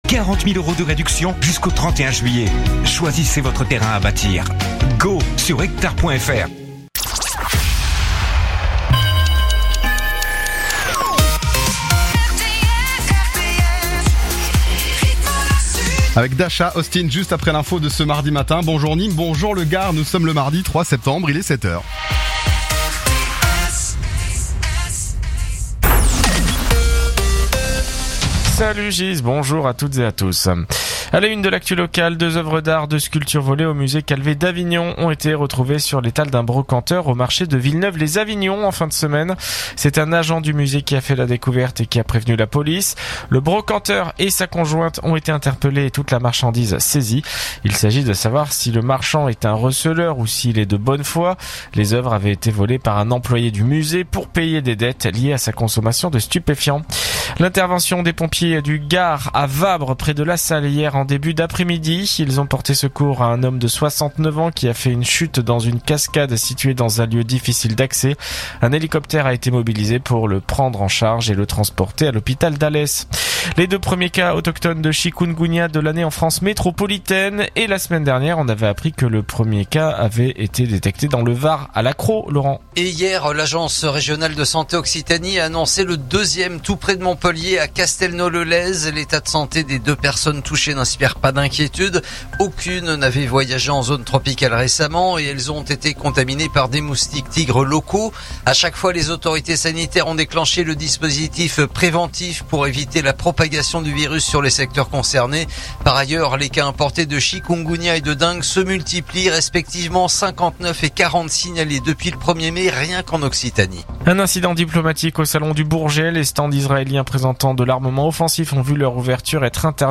Écoutez les dernières actus de Nîmes en 3 min : faits divers, économie, politique, sport, météo. 7h,7h30,8h,8h30,9h,17h,18h,19h.